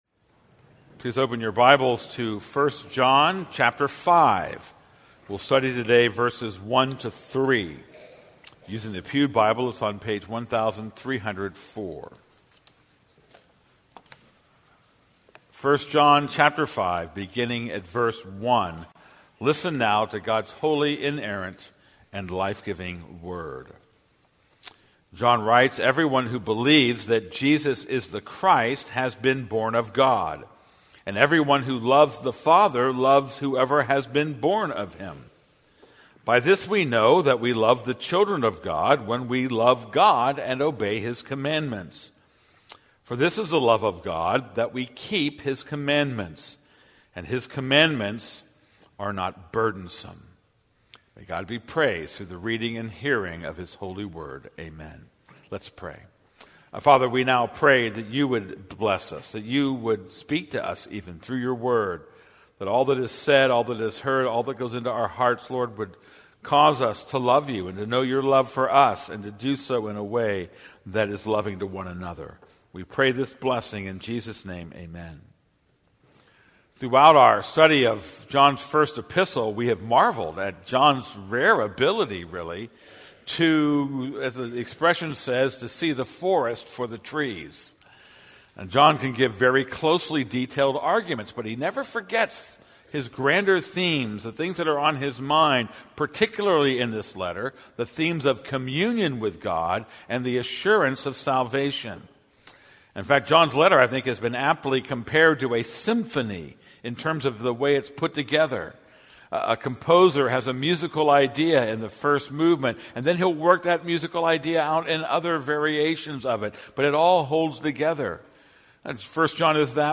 This is a sermon on 1 John 5:1-3.